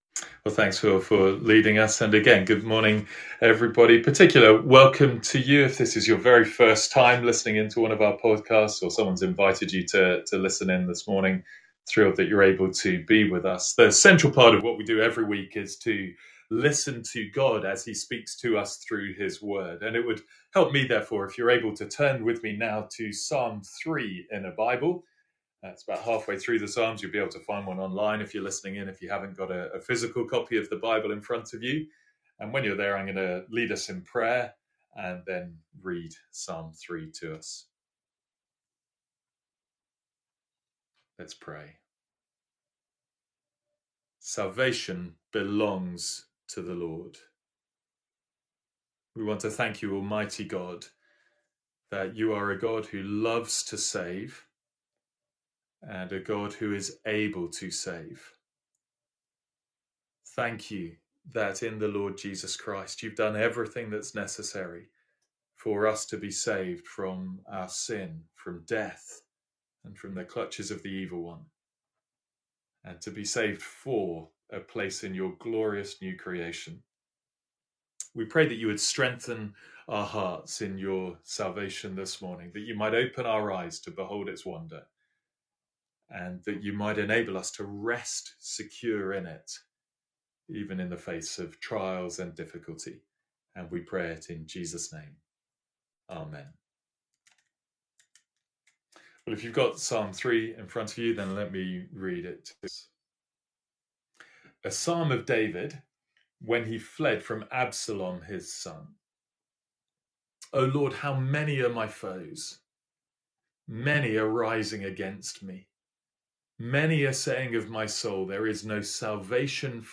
Sermons | St Andrews Free Church
From our morning series in the Psalms.